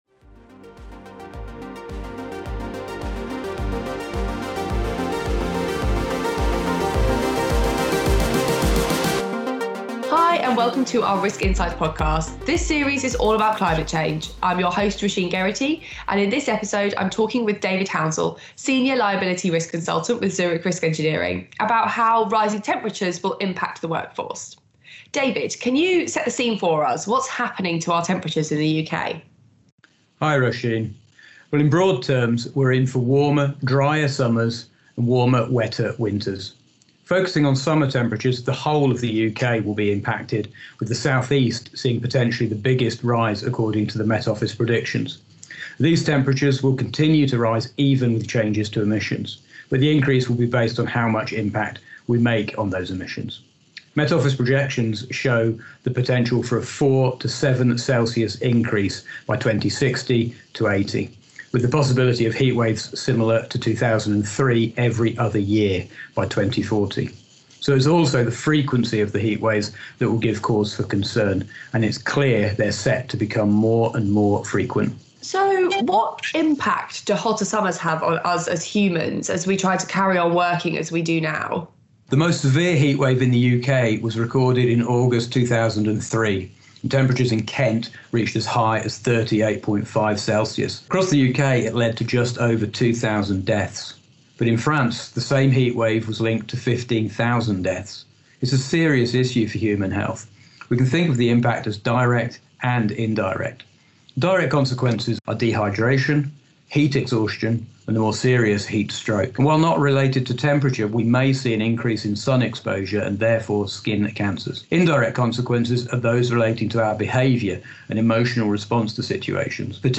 Our new brand purpose is to create a brighter future for all, so in this next series of podcasts we interview experts from across the Zurich business and explore how climate change is impacting risk management practices and how organisations can prepare for these fast changing risks.